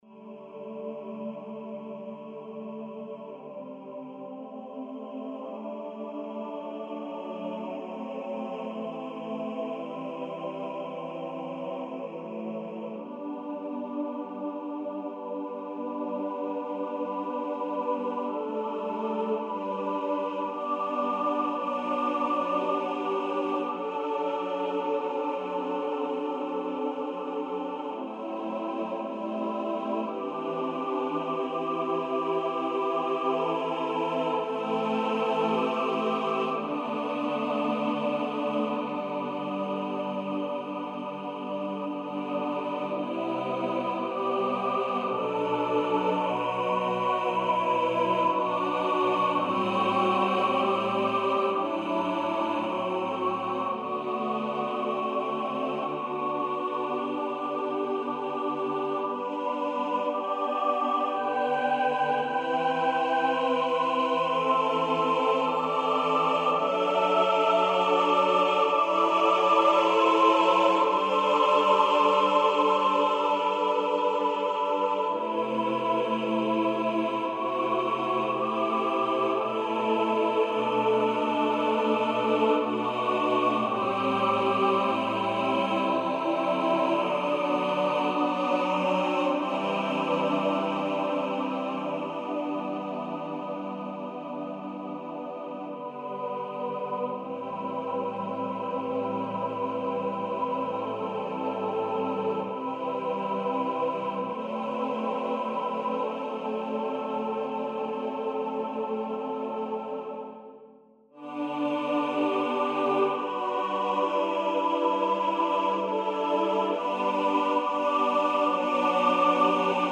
for SATB voices unaccompanied
For mixed voices (SATB) unaccompanied.